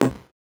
Index of /musicradar/8-bit-bonanza-samples/VocoBit Hits
CS_VocoBitC_Hit-10.wav